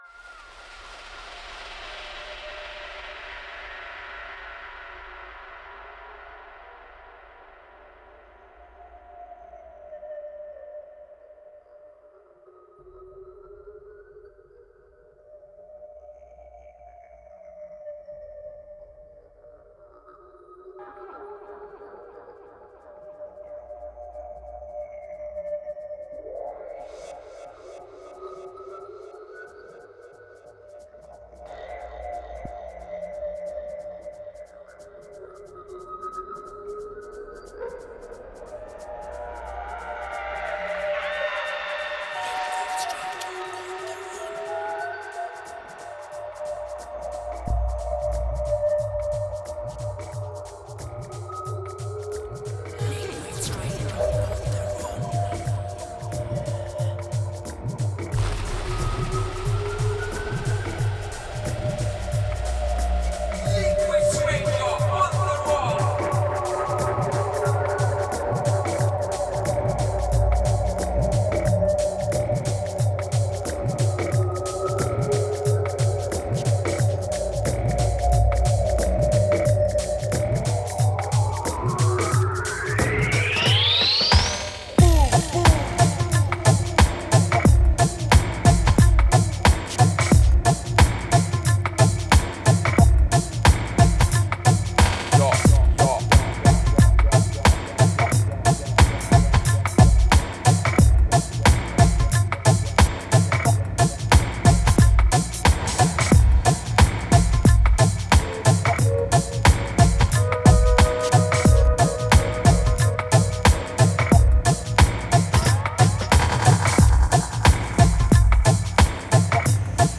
Genre: Psychill, Downtempo.